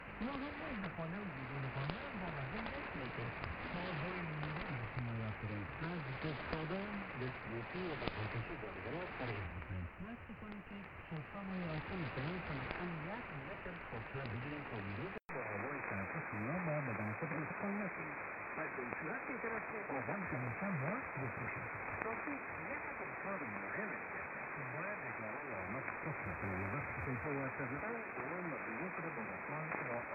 Some recordings are from stations are just above the noise floor.
Second 15 - 30: Malahit-DSP
11575khz AM
LAN-IQ-Malahit-DSP_11575khz_AM_Radio_Ashna.mp3